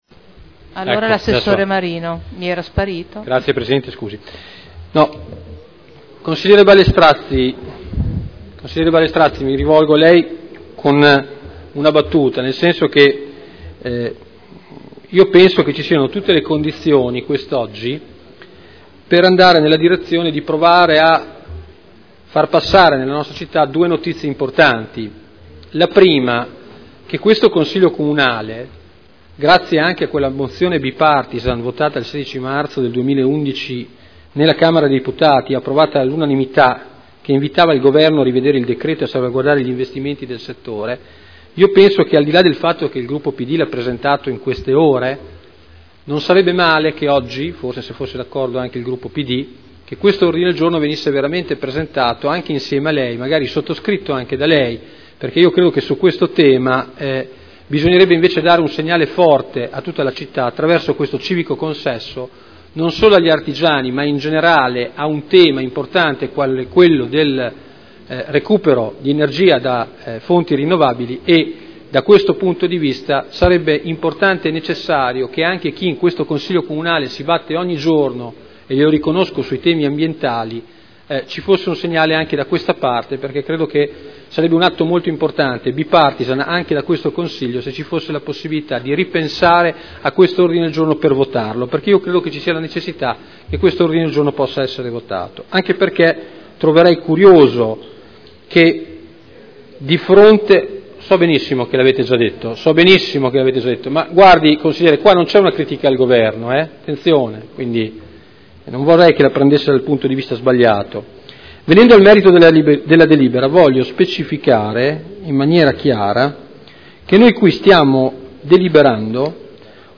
Seduta del 21/03/2011. Dibattito su proposta di deliberazione: Diritto di superficie a favore della Cooperativa Spazio Unimmobiliare per gli impianti ubicati presso la Polisportiva Saliceta San Giuliano e presso la Polisportiva Gino Nasi – Autorizzazione a iscrivere ipoteca”